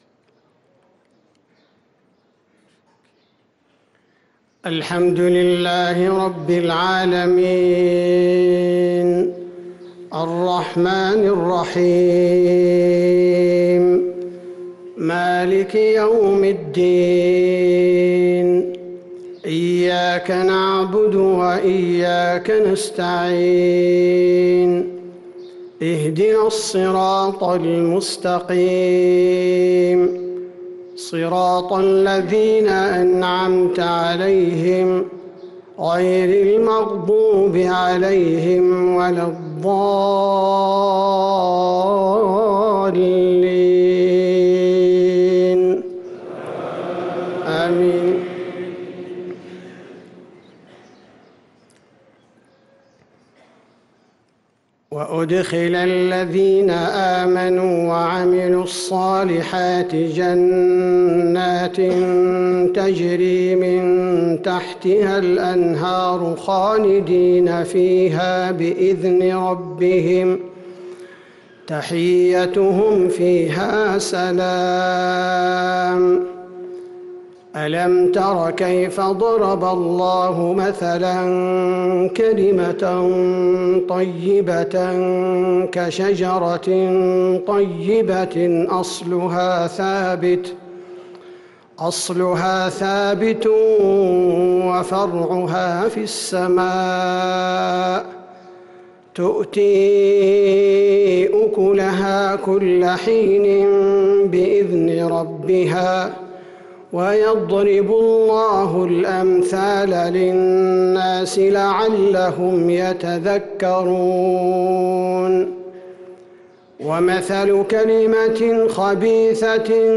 صلاة العشاء للقارئ عبدالباري الثبيتي 9 ربيع الأول 1444 هـ
تِلَاوَات الْحَرَمَيْن .